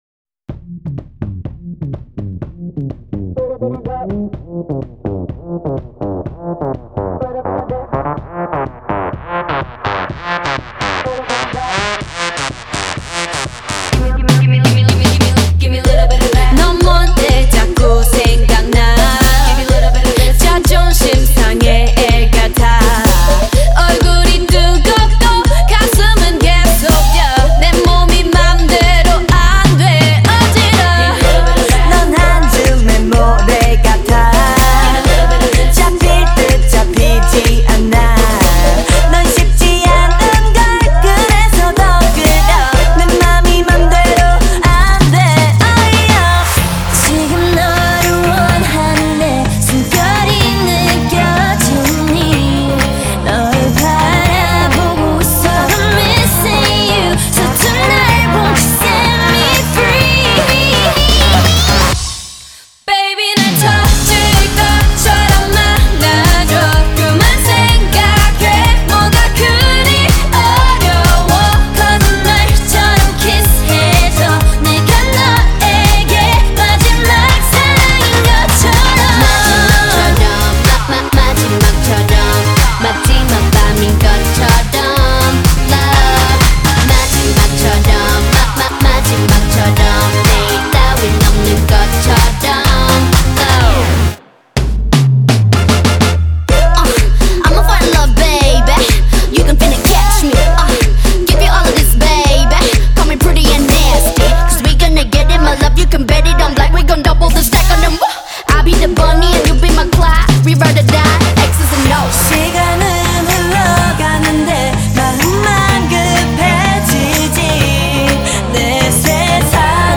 • Жанр: K-pop